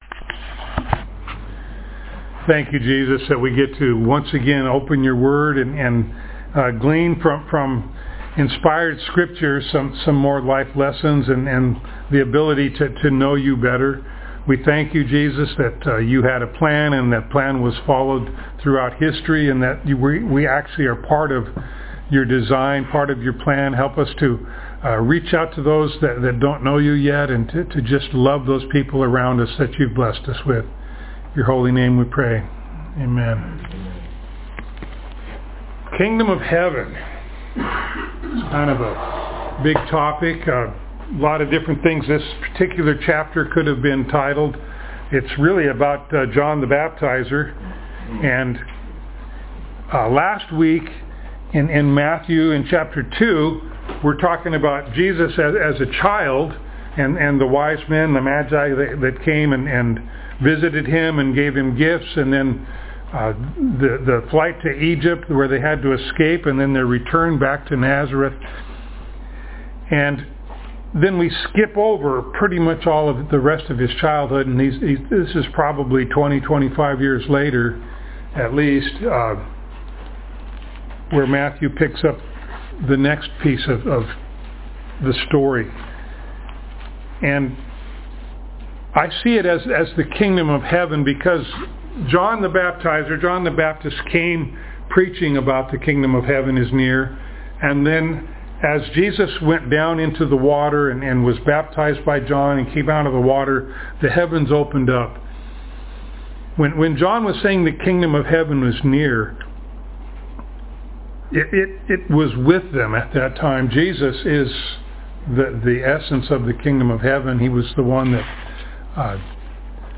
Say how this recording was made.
Passage: Matthew 3:1-17 Service Type: Sunday Morning